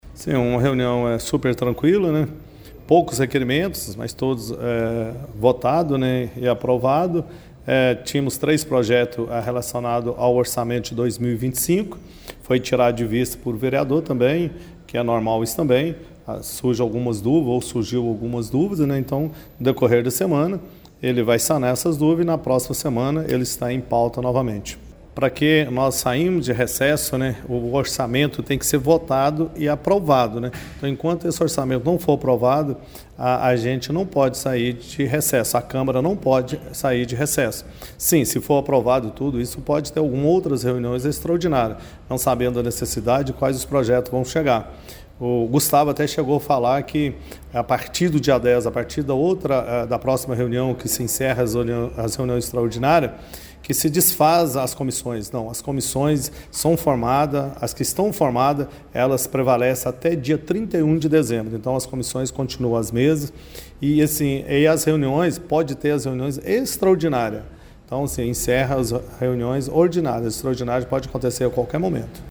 O presidente da Mesa Diretora da Câmara Municipal de Pará de Minas, Dilhermando Rodrigues Filho, disse que os três projetos devem ser pautados novamente na próxima reunião, mas caso necessário convocará extraordinária: